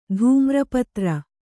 ♪ dhūmra patra